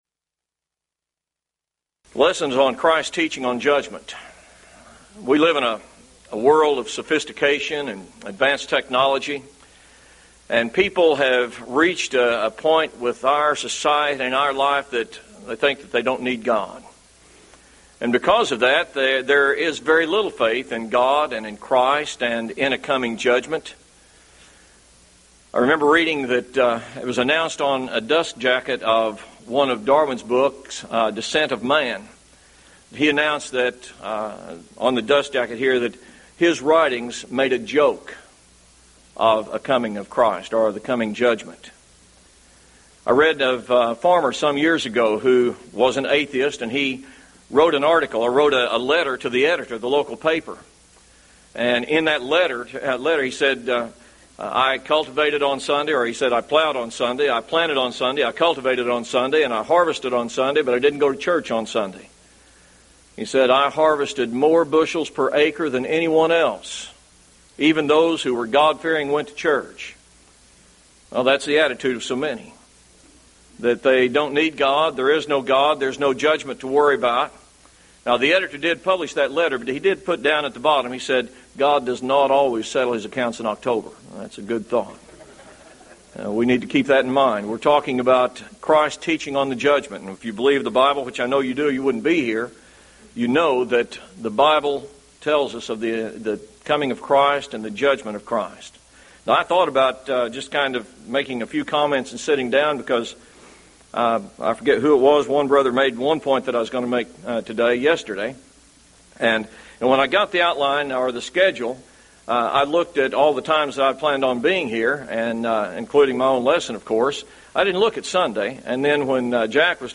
Event: 1998 Mid-West Lectures
lecture